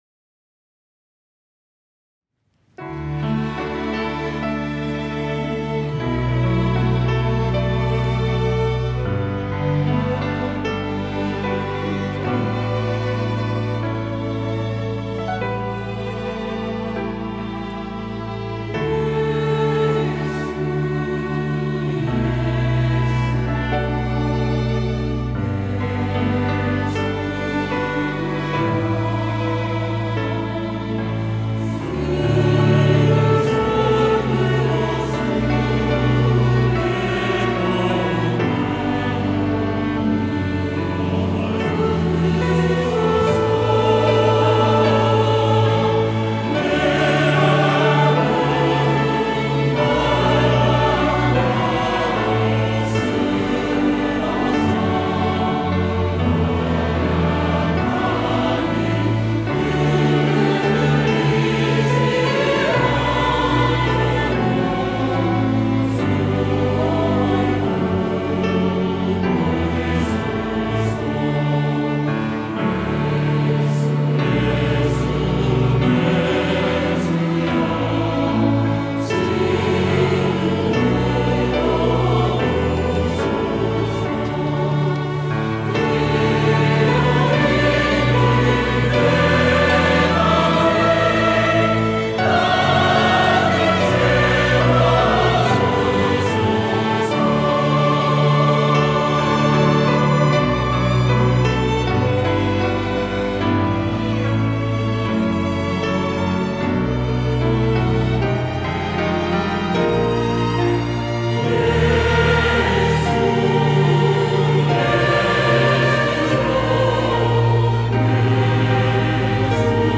찬양대 호산나